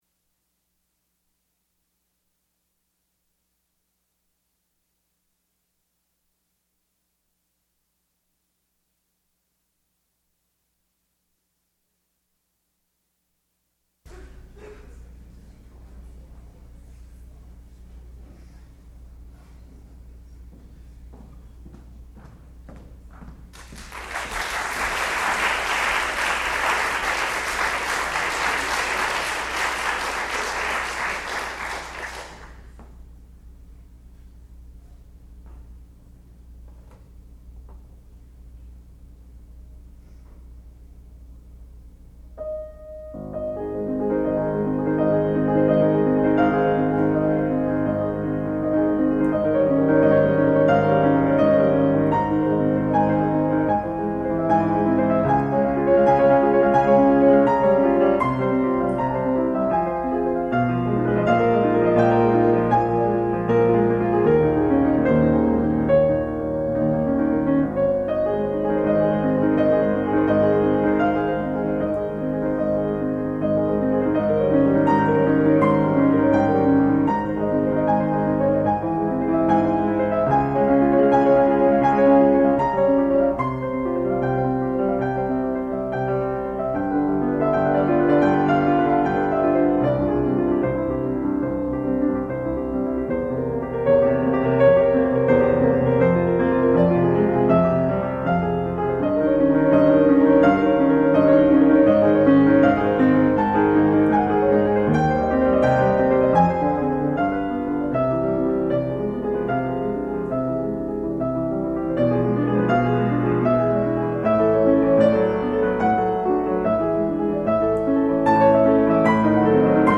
sound recording-musical
classical music
Advanced Recital